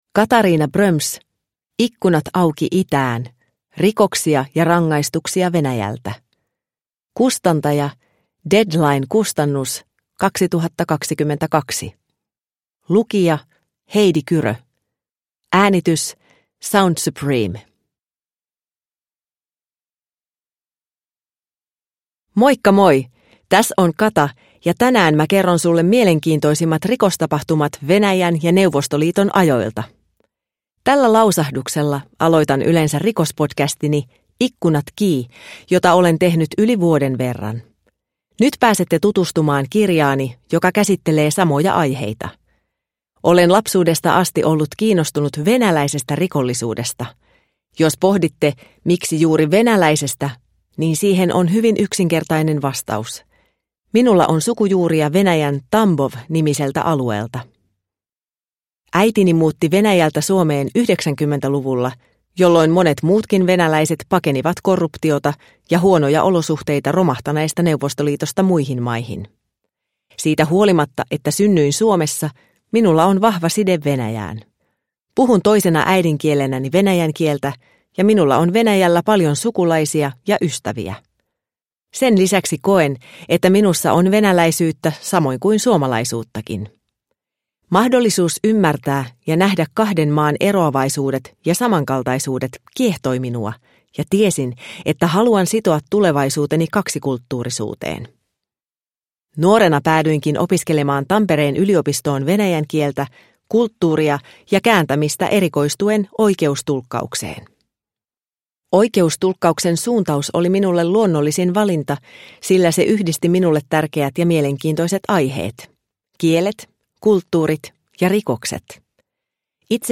Ikkunat Auki itään – Ljudbok – Laddas ner